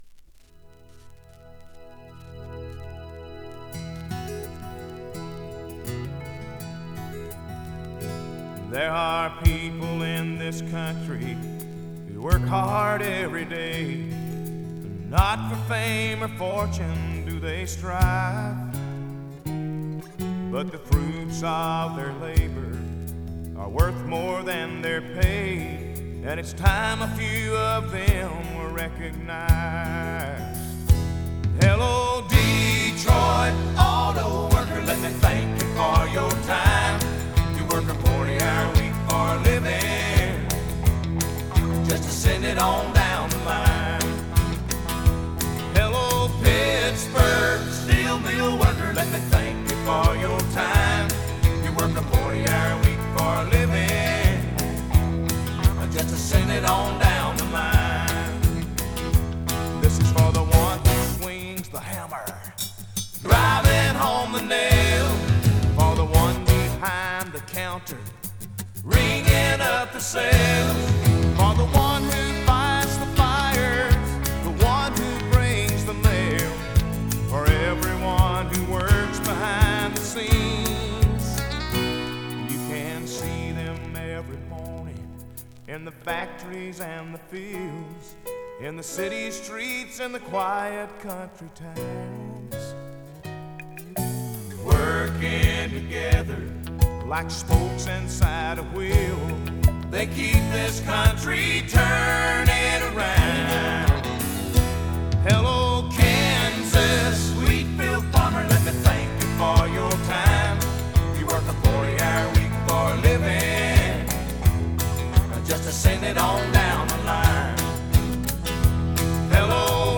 американская кантри-рок-группа.